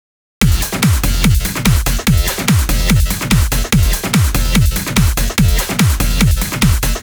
低域に耳を傾けてもらえると低いムワッとした部分が解消されキックが少しすっきりしました。